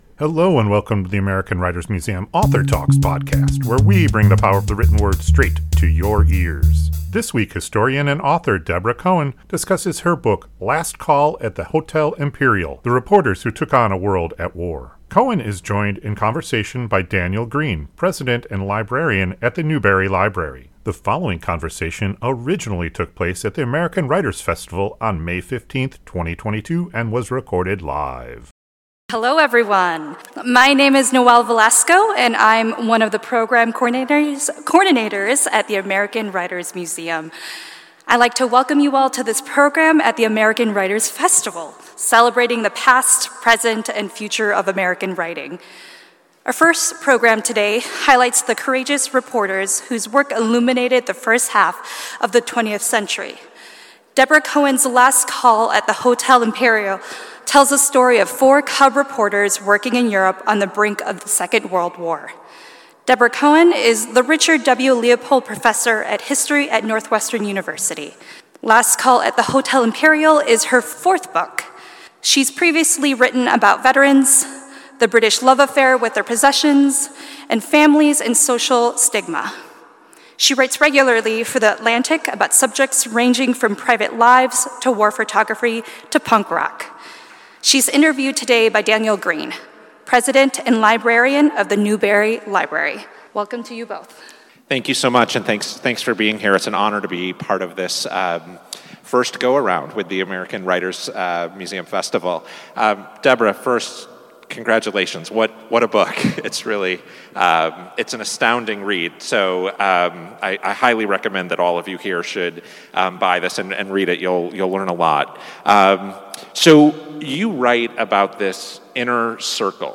The following conversation originally took place at the American Writers Festival on May 15, 2022 [...]